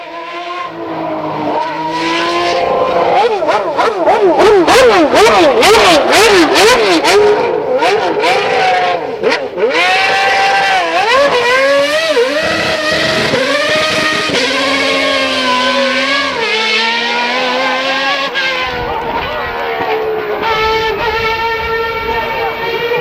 Toque para Celular Sport Car Engine Som de Alarme de Carros